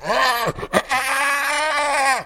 c_hyena_dead.wav